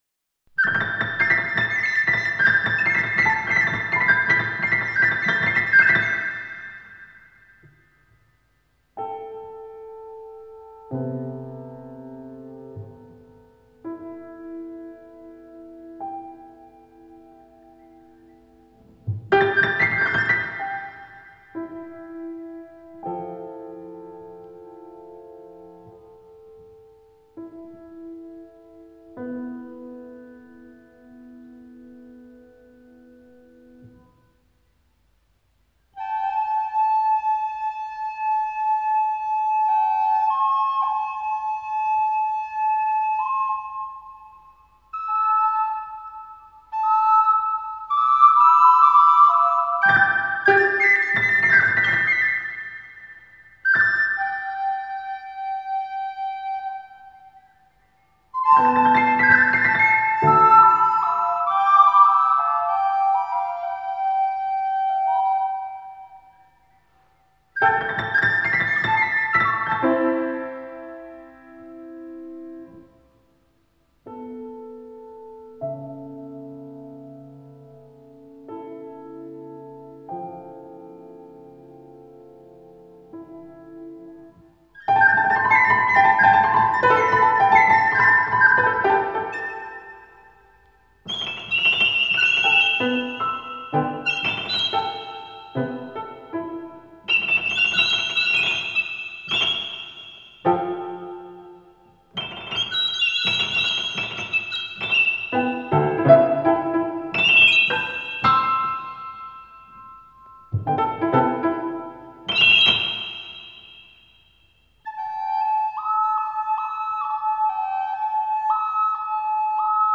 Give me your heart para voz, dos flautas dulces y piano